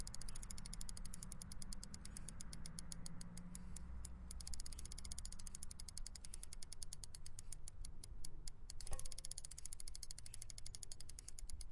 bike
描述：A bike wheel is spun and free wheels until it stops.
标签： cycle bicycle bike wheel
声道立体声